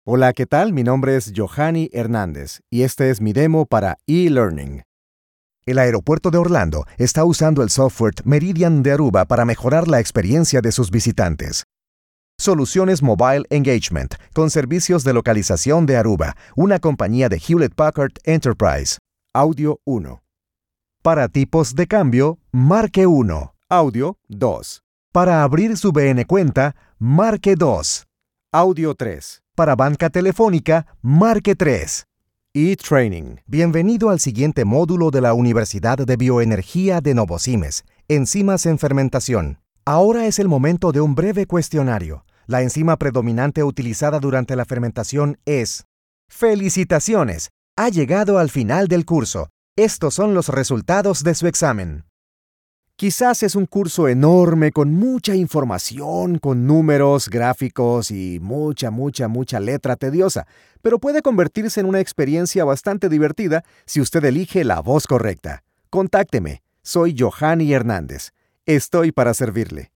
Voice actor Actor de voz y locuctor home studio español Neutro
kolumbianisch
Sprechprobe: eLearning (Muttersprache):
Demo E-learning 3 160KBPS baja.mp3